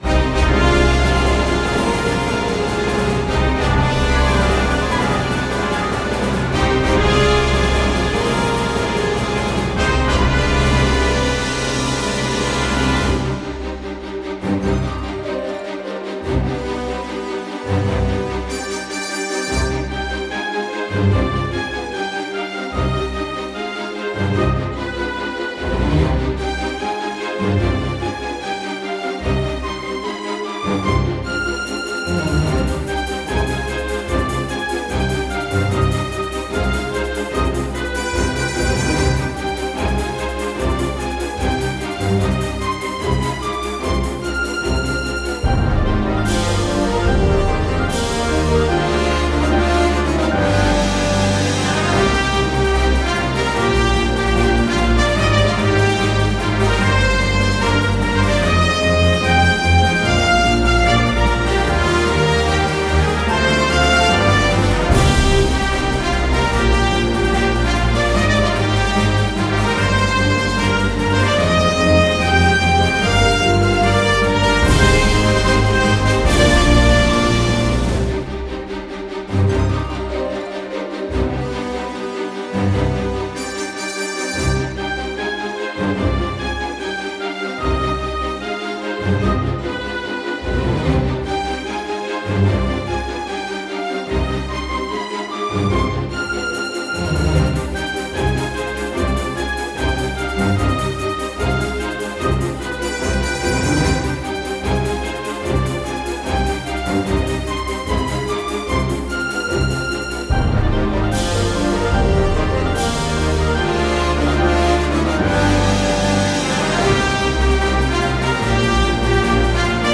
BGM一覧（雰囲気）
白熱するオーケストラ戦闘曲